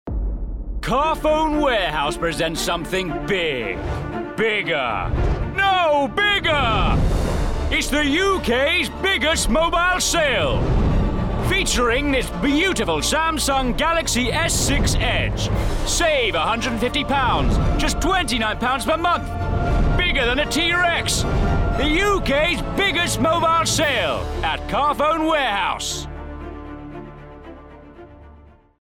20/30's RP/London, Confident/Natural/Direct
Commercial Showreel Holland and Barrett Innocent Pimms Natwest